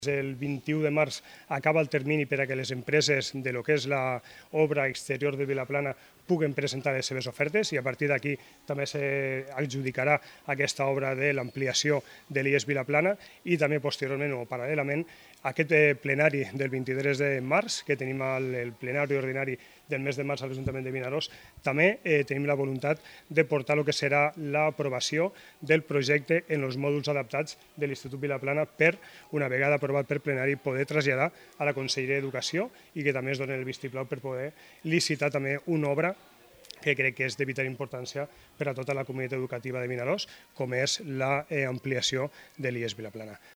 Raquel Tamarit, consellera d’Educació,Cultura i Esport
Ximo Puig, president de la Generalitat Valenciana
Guillem Alsina, alcalde